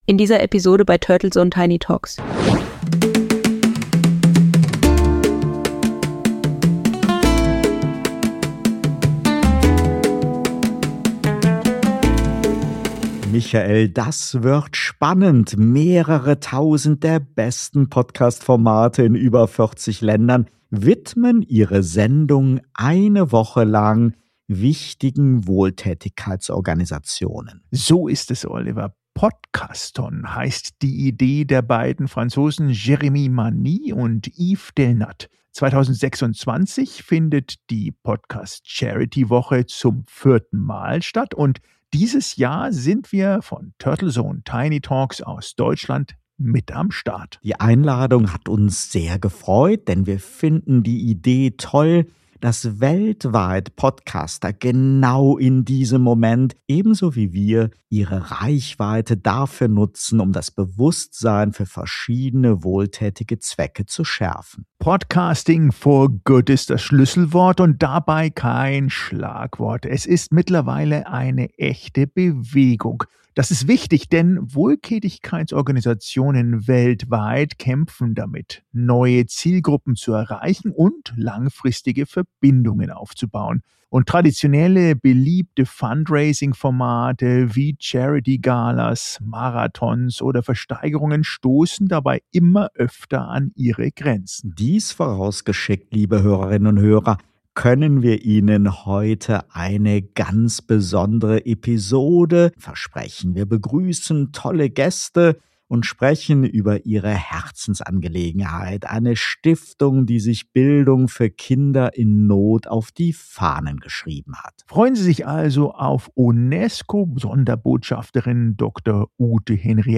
Außerdem sprechen wir über rollende Schulen in Bangladesch. Weitere Themen des 60-minütigen Podcast-Talks: Die Wirkung von Spenden und die Herausforderungen von Wohltätigkeitsorganisationen.